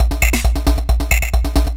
DS 135-BPM D8.wav